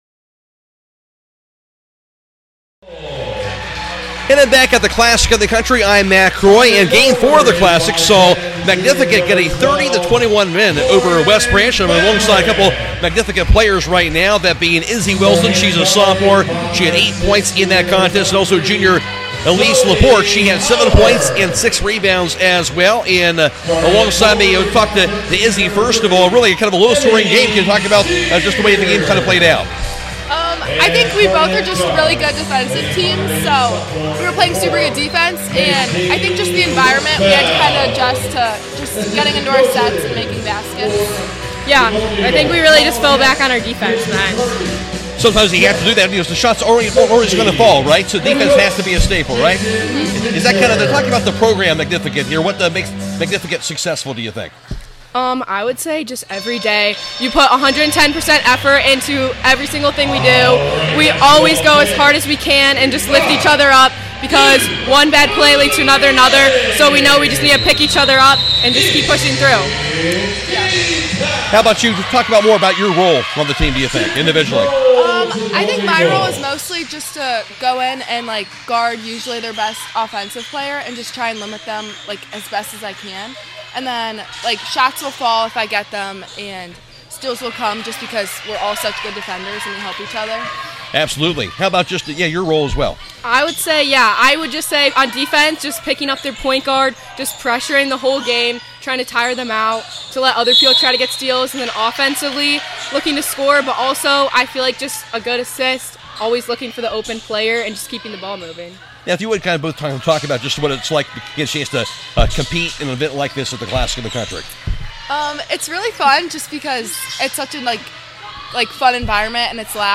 2026 CLASSIC MAGNIFICATE PLAYER INTERVIEWS